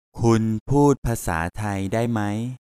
M